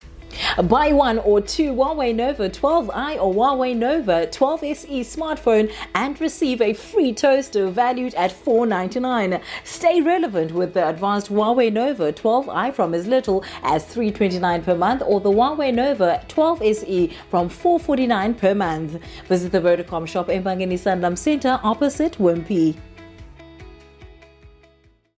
authentic, authoritative, soothing
Vodacom advert demo